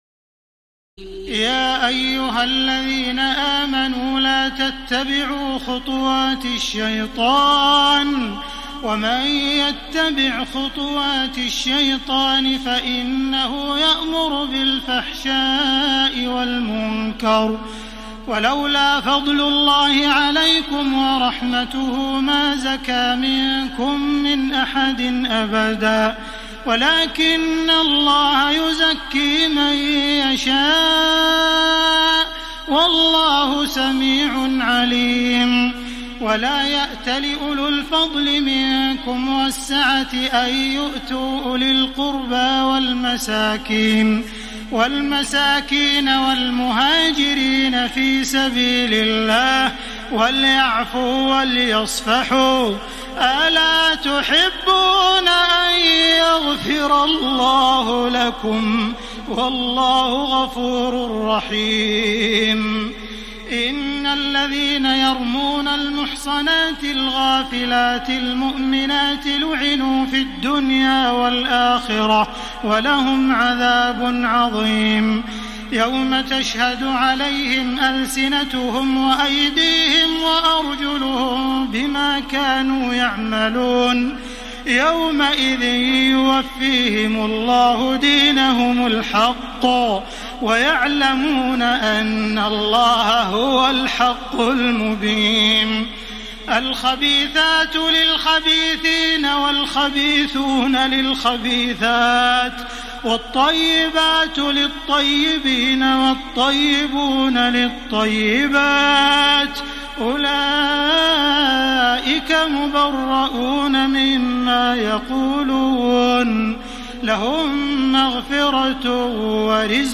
تراويح الليلة السابعة عشر رمضان 1433هـ من سورتي النور (21-64) و الفرقان (1-20) Taraweeh 17 st night Ramadan 1433H from Surah An-Noor and Al-Furqaan > تراويح الحرم المكي عام 1433 🕋 > التراويح - تلاوات الحرمين